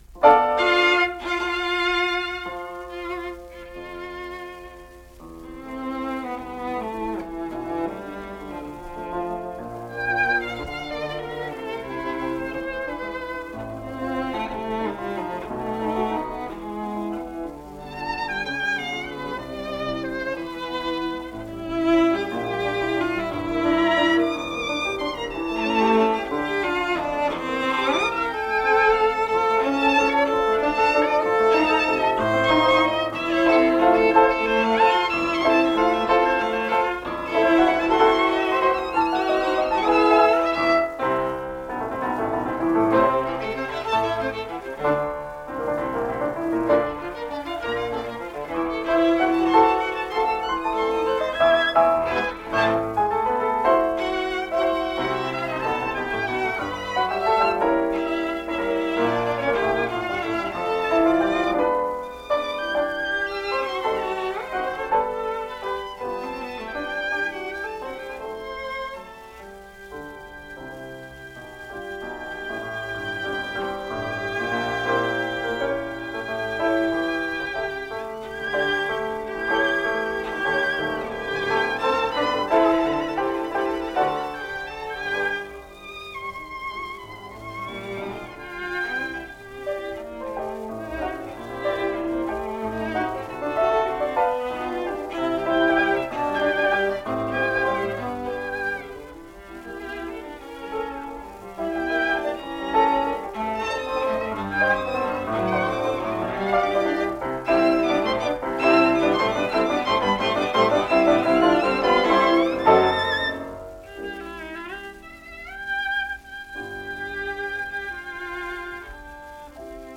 piano
violin
cello – Paris Radio session
cello in this Paris radio studio recital, circa 1954.
Lalo-Trio.mp3